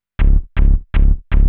hous-tec / 160bpm / bass